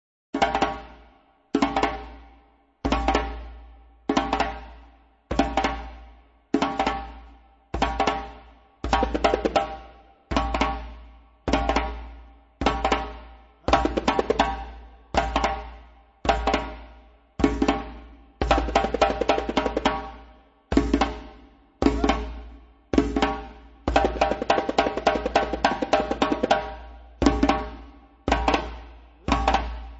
Appel du tam-tam a l'unite'